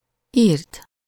Ääntäminen
IPA : /ˈrɪtn̩/